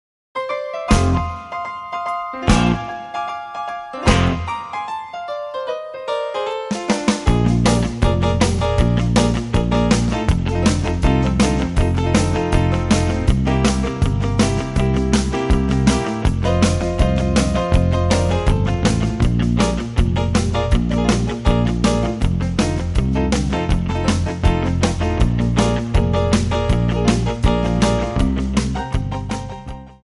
Backing track files: Country (2471)
Buy With Backing Vocals.